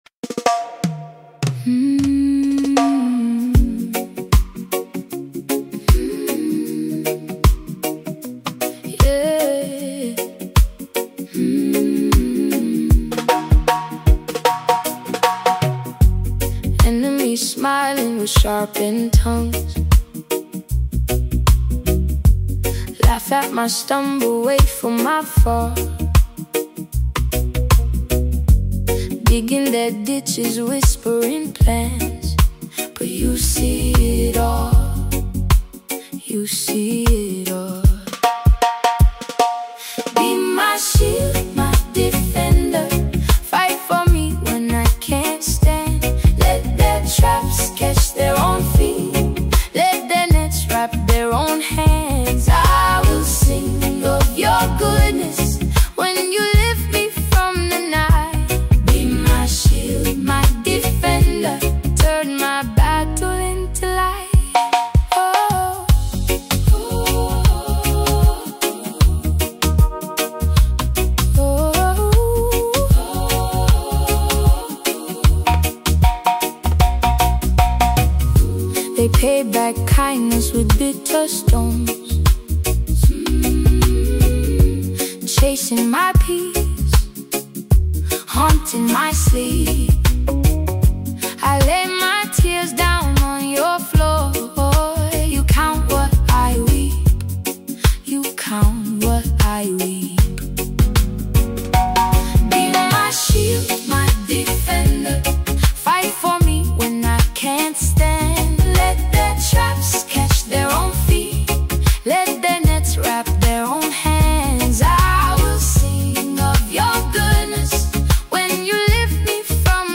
returns with a powerful and reflective tune titled